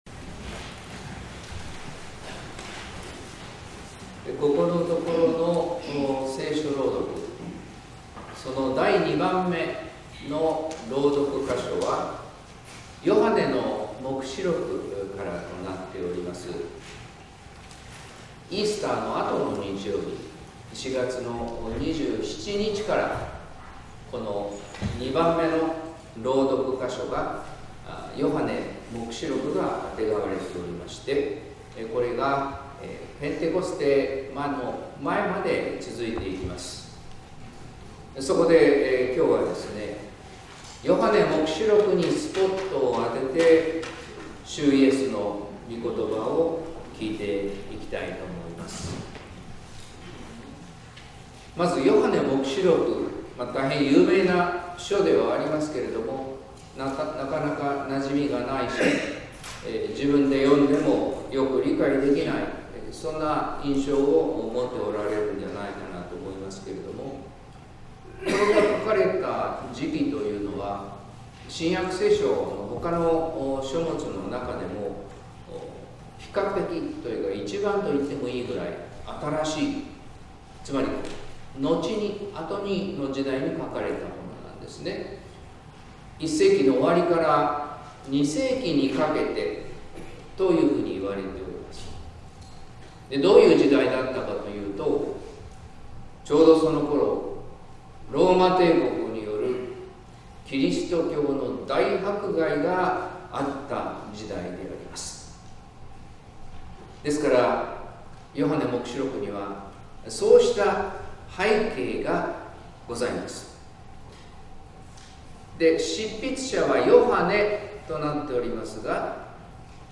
説教「霊に満ちたイエスの言葉」（音声版）